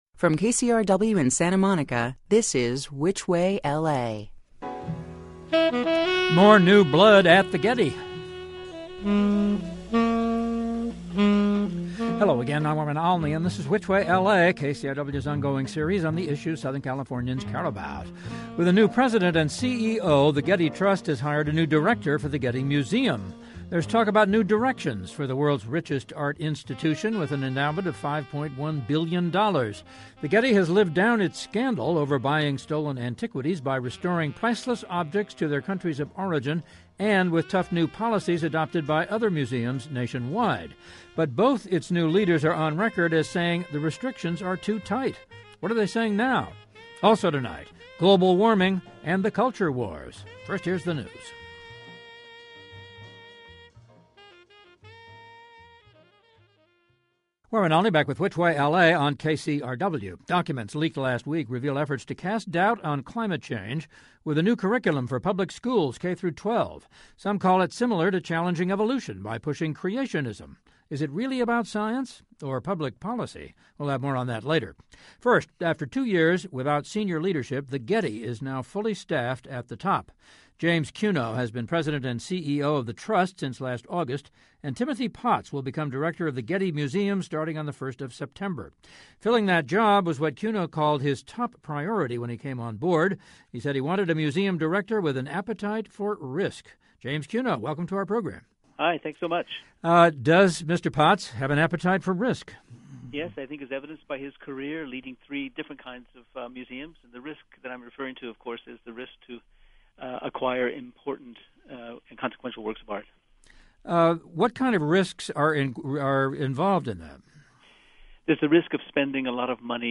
Getty CEO James Cuno discussed his “appetite for risk,” his decision to hire Timothy Potts as the Getty’s next museum director and his vision for the museum in an interview on Warren Olney’s Which Way LA program on KCRW.